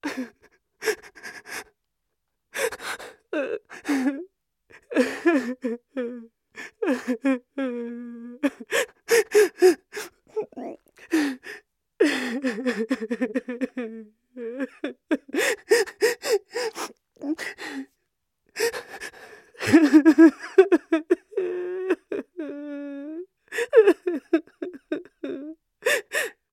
SFX女光人哭(humancrylightfemale)音效下载
这是一个免费素材，欢迎下载；音效素材为女光人哭(humancrylightfemale)， 格式为 wav，大小5 MB，源文件无水印干扰，欢迎使用国外素材网。